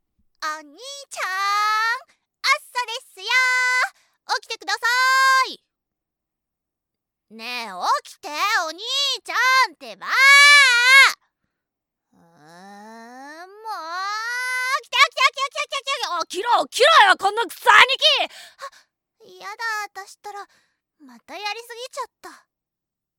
■ボイスサンプル■
かわいい妹.mp3